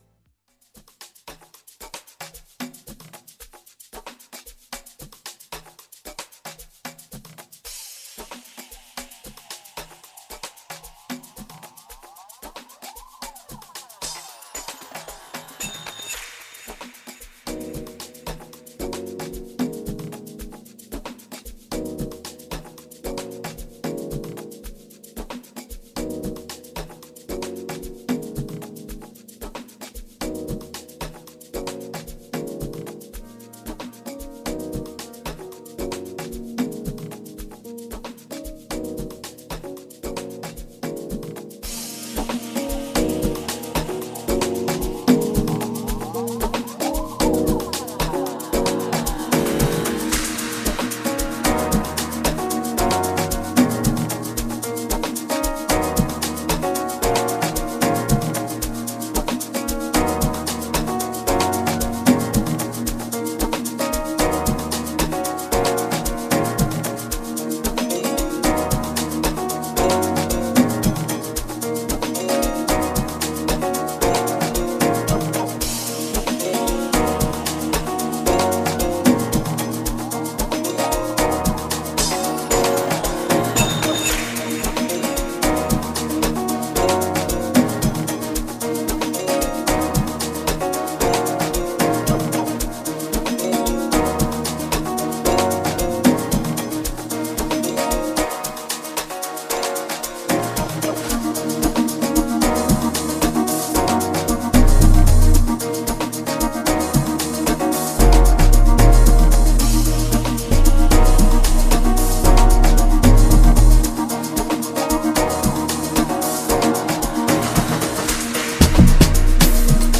an exclusive mix that’s filled with some of banging tunes